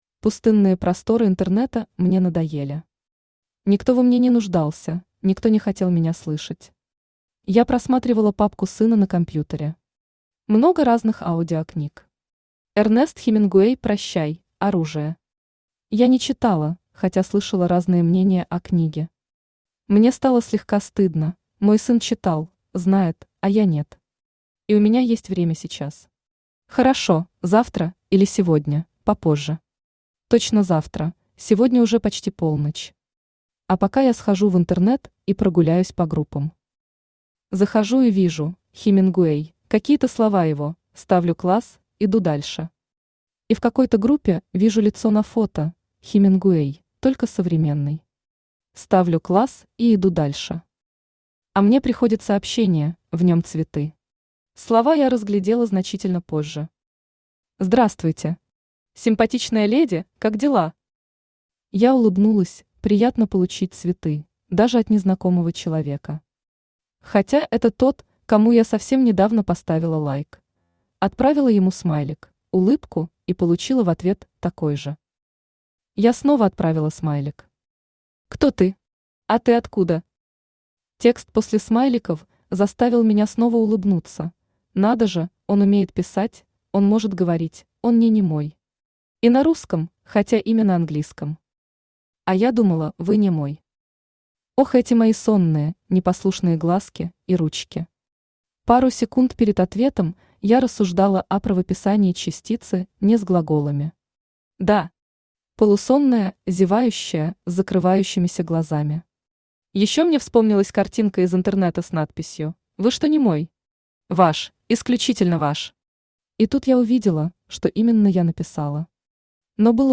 Аудиокнига Как ты спала?
Автор Светлана Екимова Читает аудиокнигу Авточтец ЛитРес.